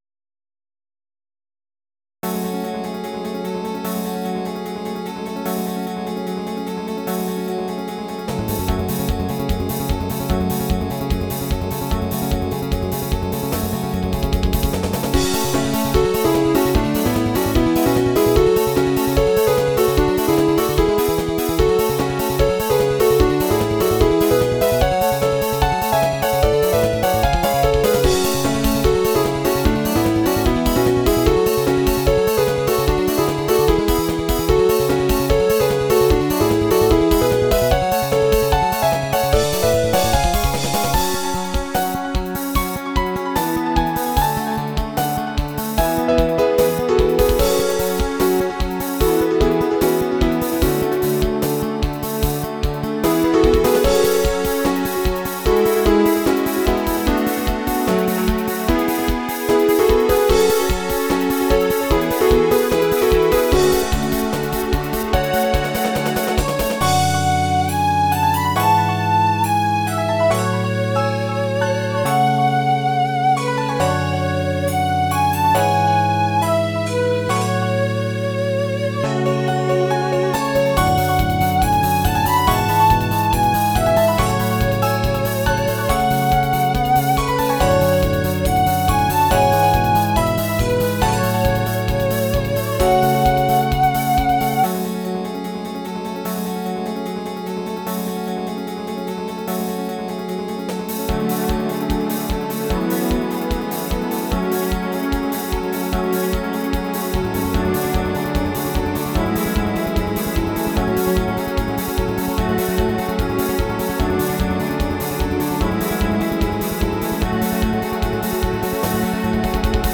由Virtual Sound Canvas的SC-88Pro内录